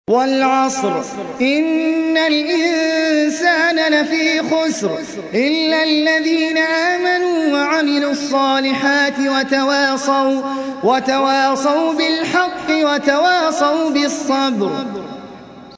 سورة العصر | القارئ أحمد العجمي